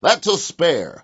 gutterball-3/Gutterball 3/Commentators/Baxter/wack_thatsaspare.wav at 608509ccbb5e37c140252d40dfd8be281a70f917